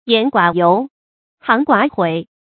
言寡尤，行寡悔 yán guǎ yóu，xíng guǎ huǐ
言寡尤，行寡悔发音